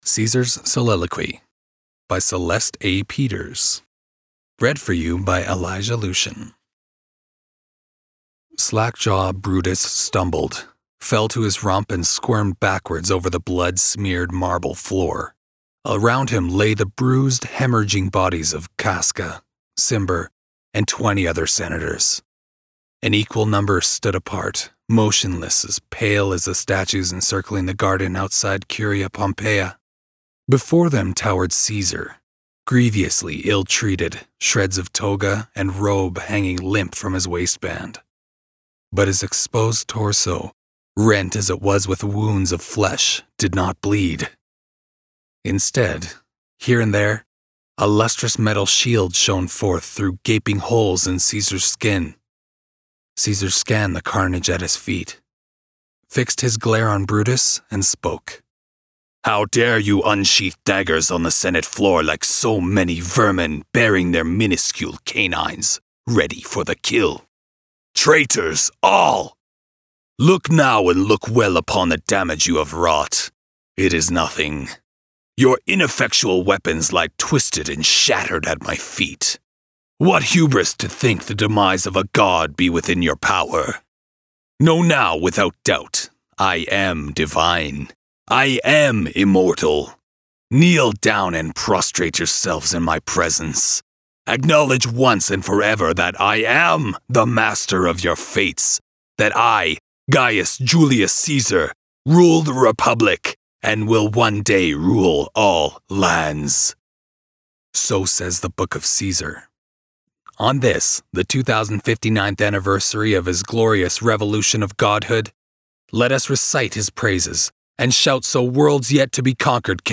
Calgary voice actor
flash fiction readings